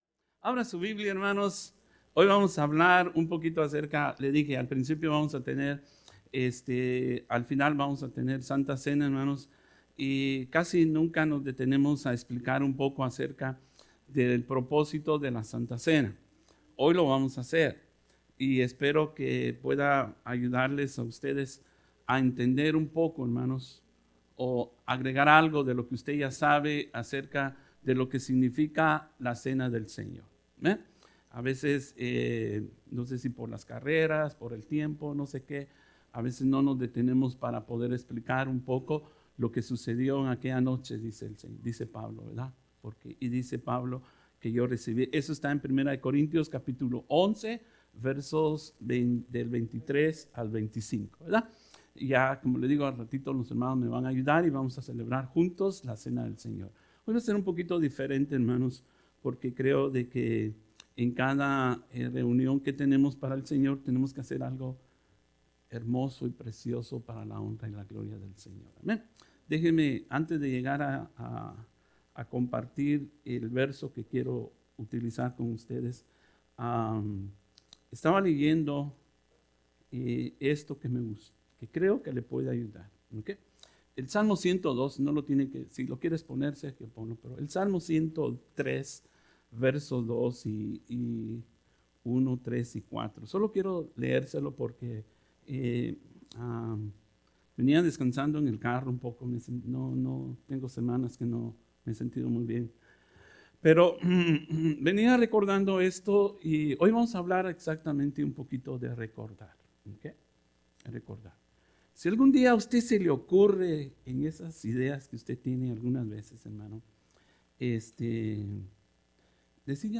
Sermons | Mercy Springs Church of the Nazarene